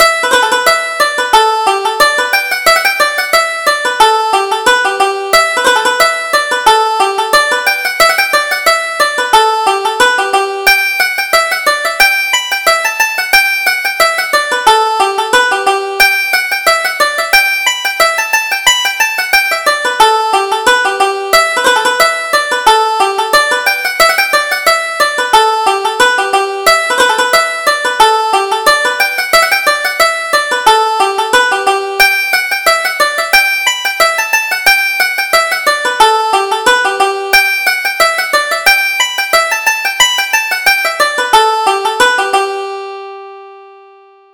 Reel: The Old Maids of Galway